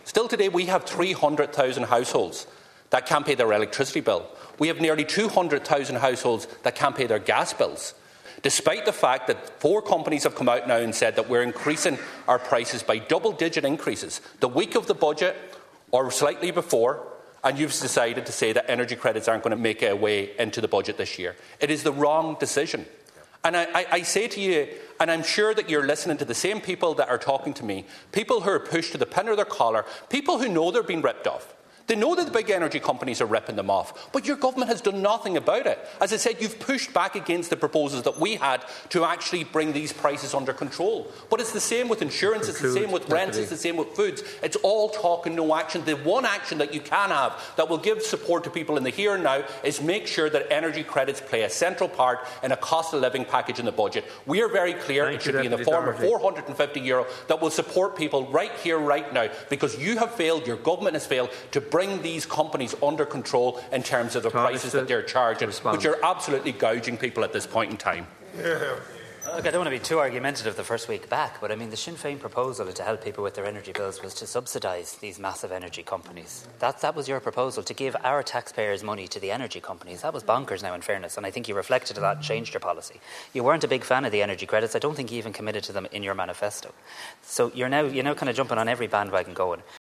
Speaking in the Dail a short time ago, Donegal Deputy Pearse Doherty hit out at energy companies who have announced price increases at a time when he says people can’t catch a breath with the cost of living crisis.